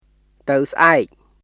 [タウ・スアエク　tə̀w sʔaek]